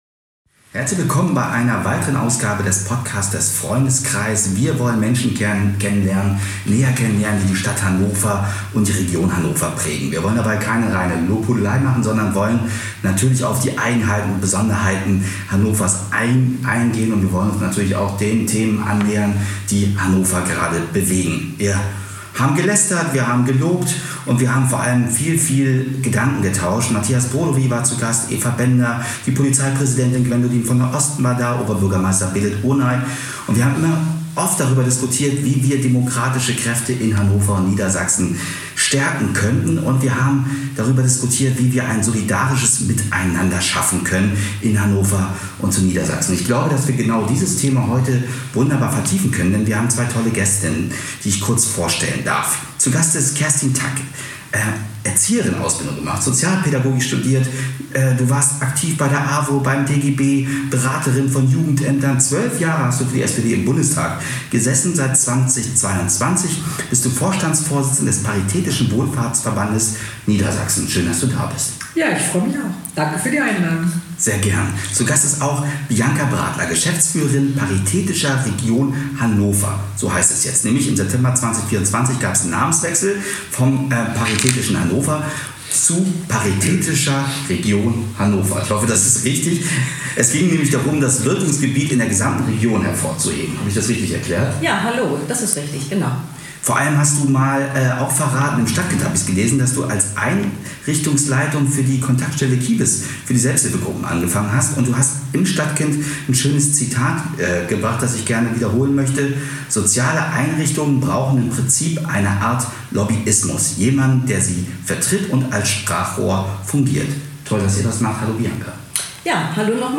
Sorry, heute las letzte Mal mit "Waschmaschienensound" Mehr